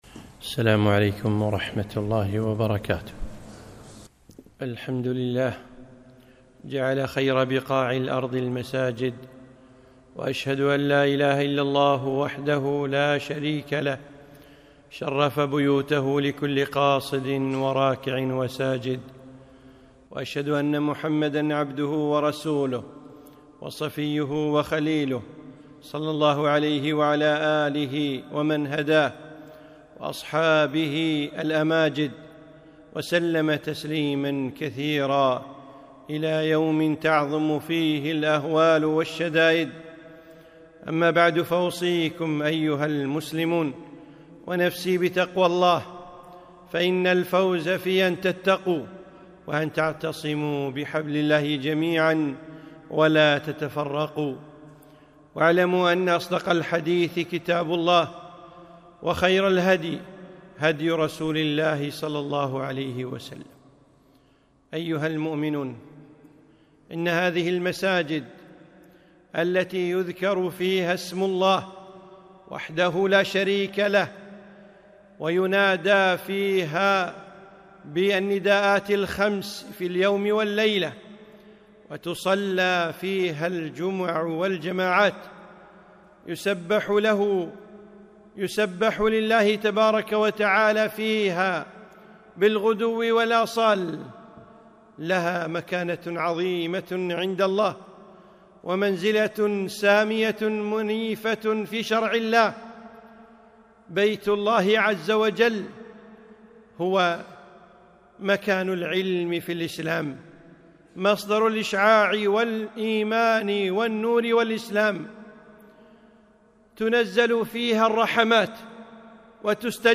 خطبة - المسجد في الإسلام